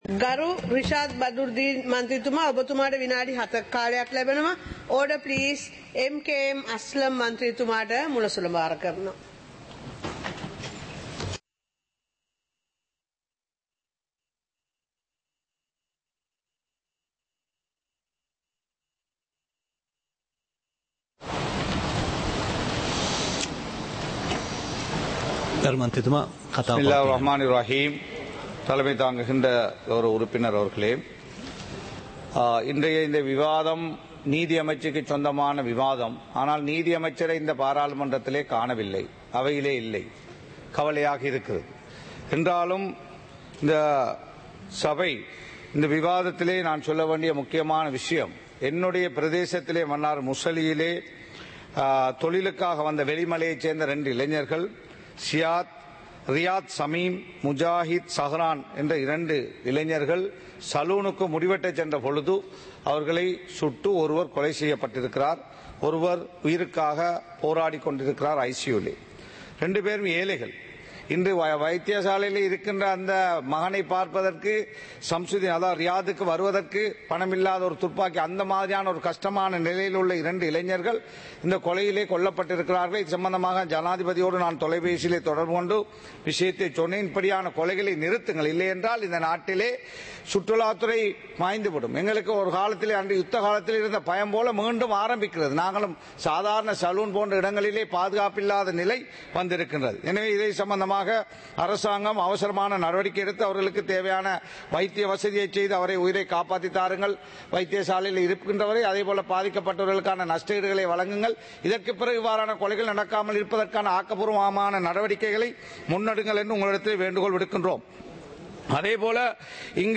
சபை நடவடிக்கைமுறை (2026-02-19)
நேரலை - பதிவுருத்தப்பட்ட